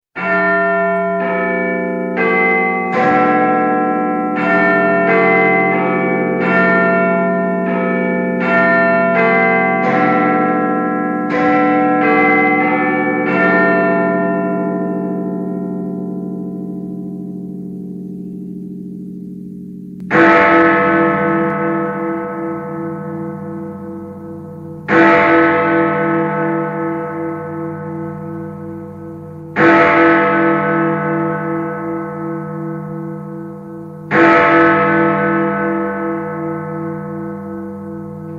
Ses Efektleri